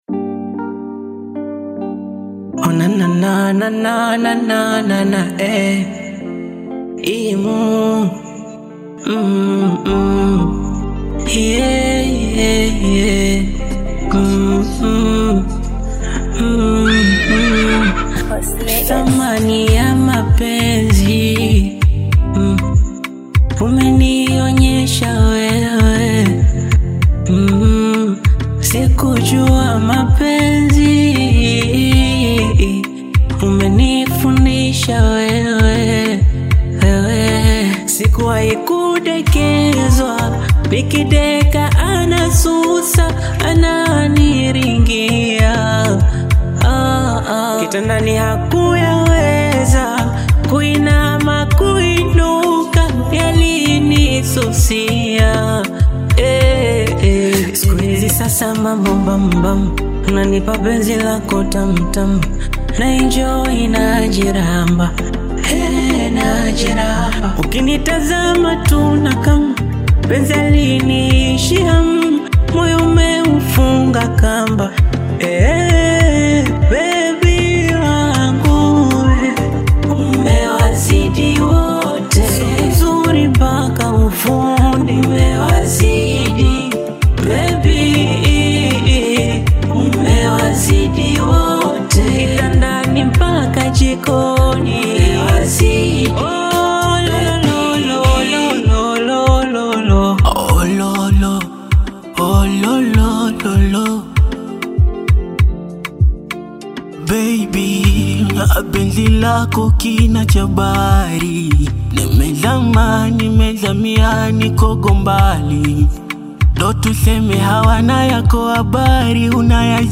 Tanzanian music
adds emotional balance and melodic strength to the song